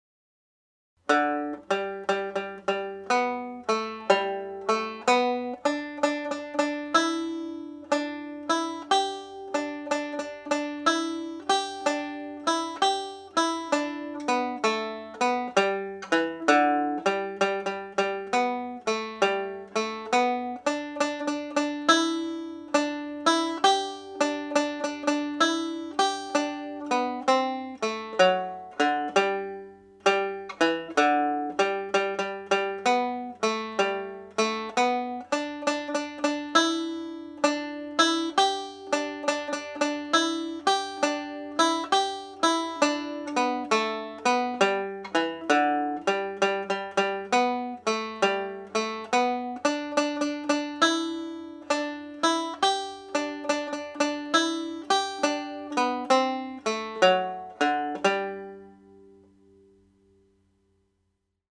A reel in the key of G major populated with lots of triplets…
first part played slowly